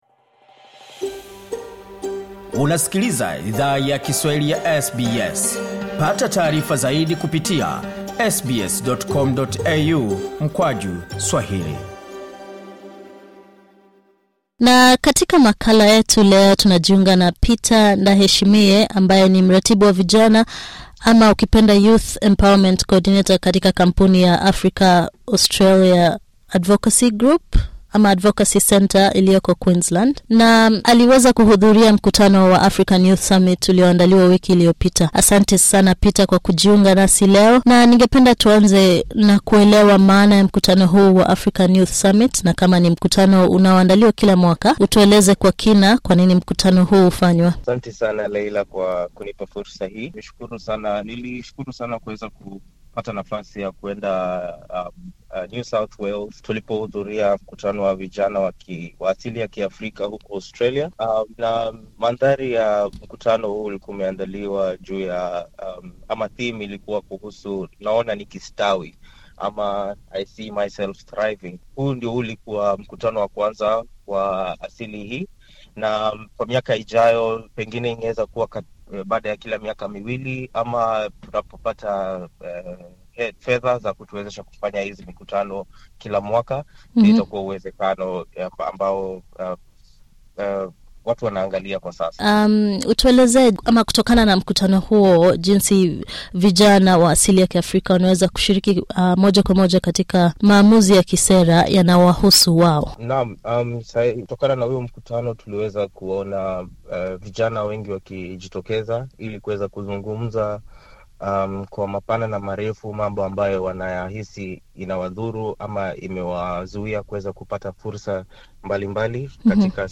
Mkutano wa kwanza wa African Youth Summit uliandaliwa na kufanyika mwaka huu, na mratibu wa vijana aliyehudhuria anajiunga nasi kuzungumzia mada ya mkutano huo.